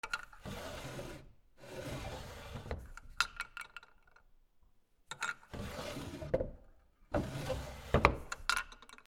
『シュー』